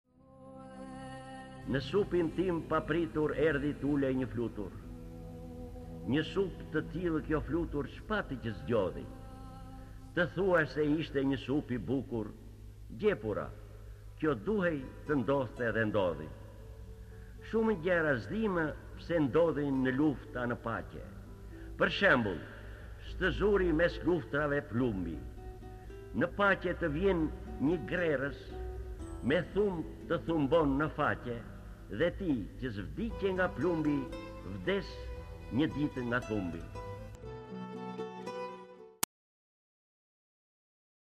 D. AGOLLI - RASTËSITË Lexuar nga D. Agolli KTHEHU...